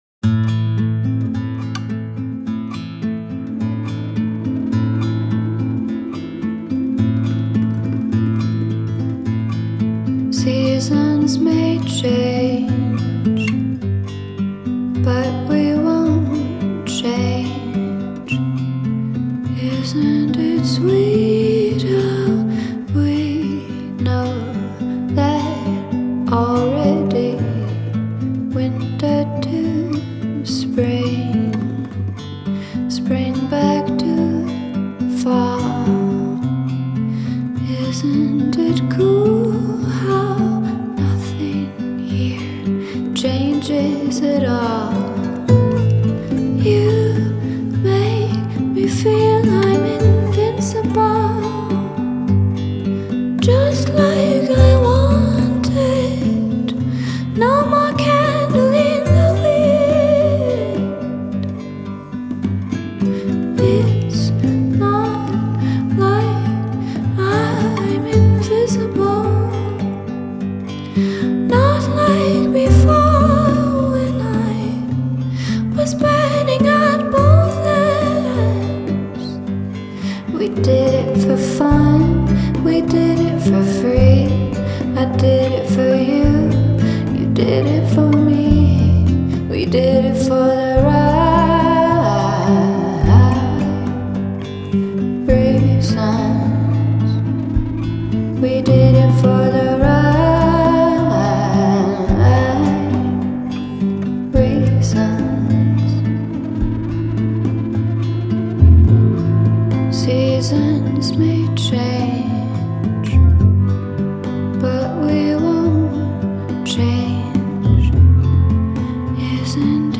создавая интимное и мечтательное настроение.